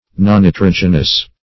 Search Result for " nonnitrogenous" : The Collaborative International Dictionary of English v.0.48: Nonnitrogenous \Non`ni*trog"e*nous\, a. Devoid of nitrogen; as, a nonnitrogenous principle; a nonnitrogenous food.